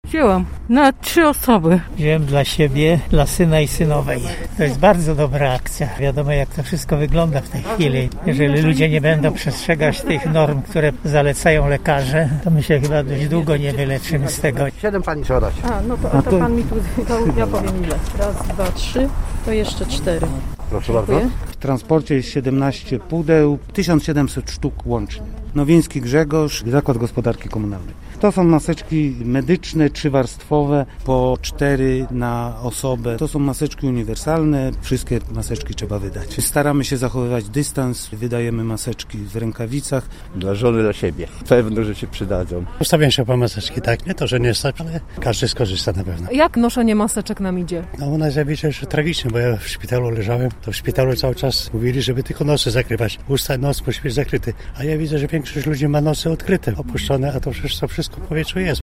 Na jedną osobę przypadają cztery maseczki. Spora kolejka ustawiła się w punkcie przy ul. Rzeźniczaka: